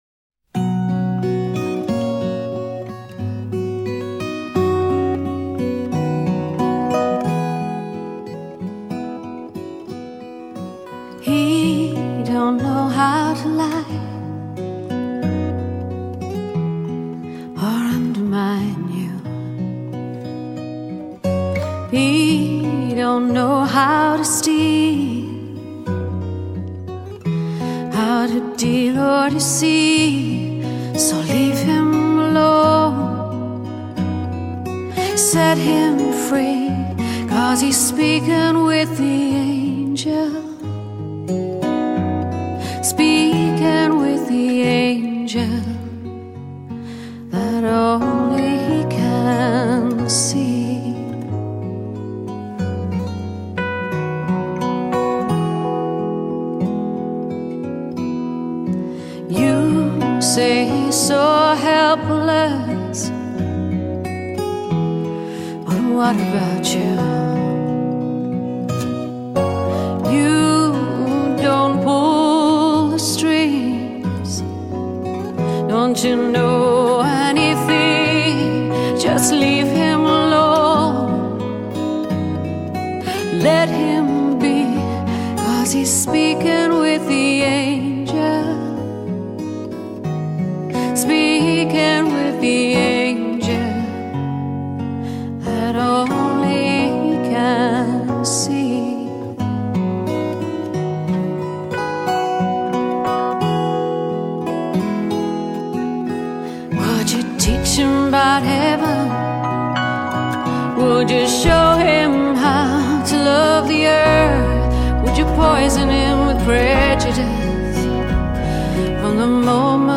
她的歌永远给人以平静诗意的感受，富有穿透力的嗓音纯净而刚烈，带着十足的爱尔兰理性。